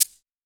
SIZZLE_3.wav